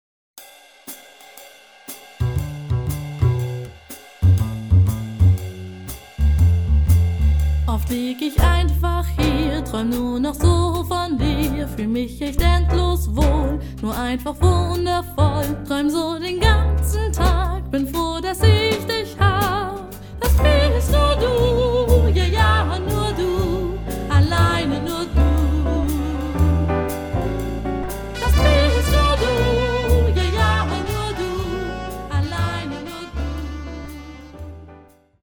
Genre: Swing